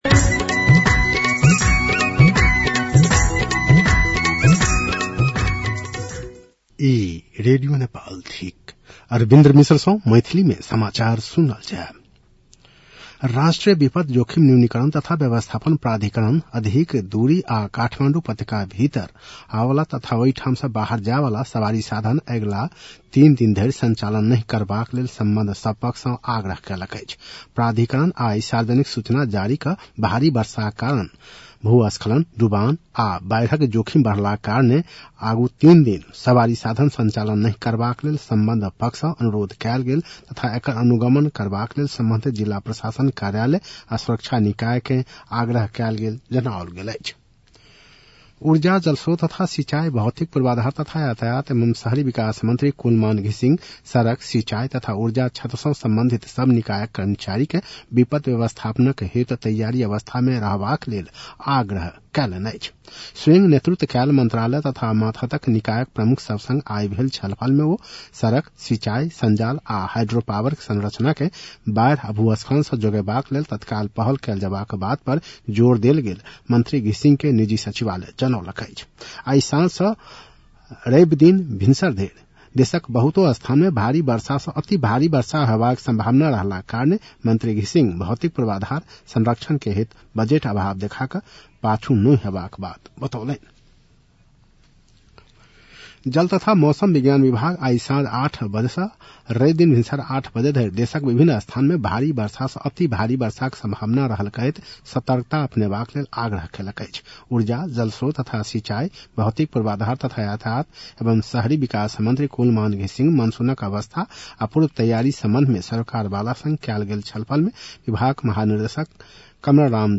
मैथिली भाषामा समाचार : १८ असोज , २०८२